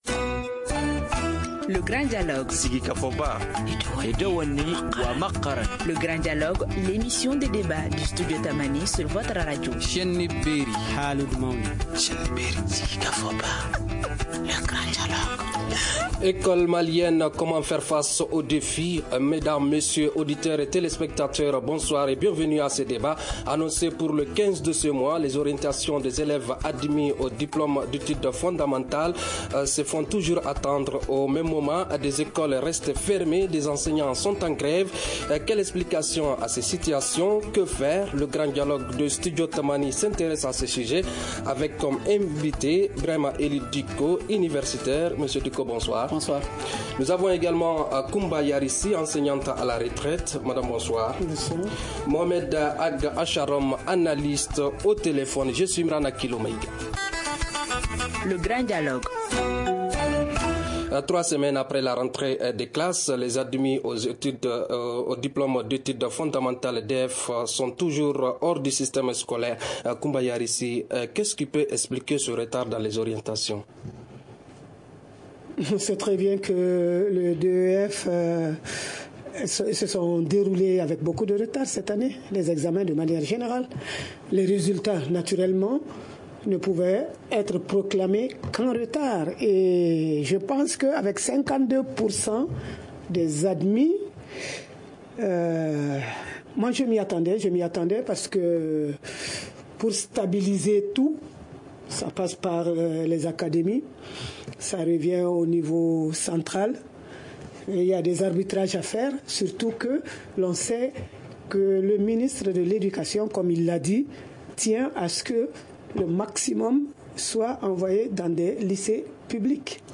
enseignante à la retraire au téléphone
analyste.